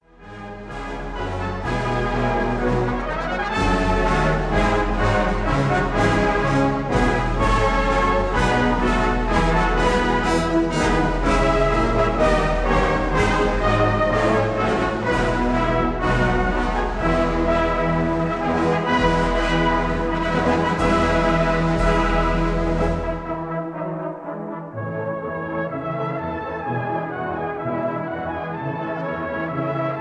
brass bands
1960 stereo recording